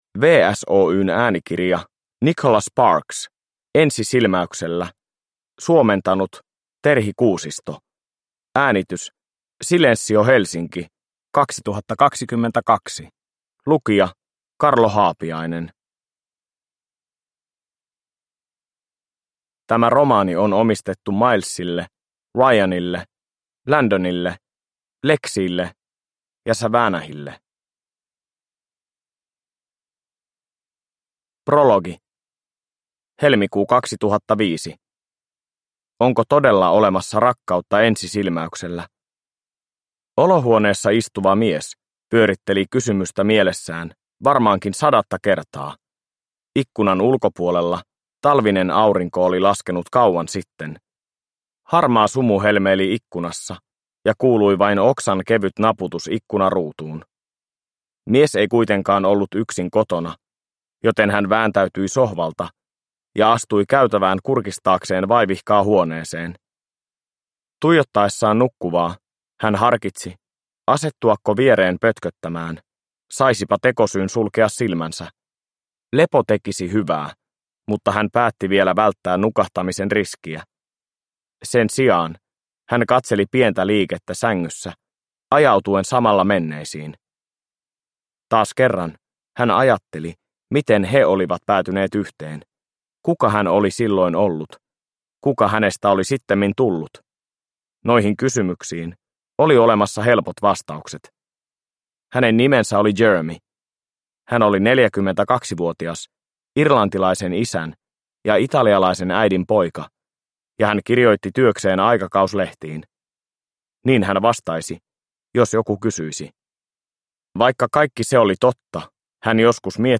Ensi silmäyksellä – Ljudbok – Laddas ner